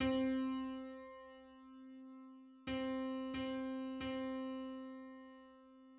Just: 513/512 = 3.38 cents.
Public domain Public domain false false This media depicts a musical interval outside of a specific musical context.
Five-hundred-thirteenth_harmonic_on_C.mid.mp3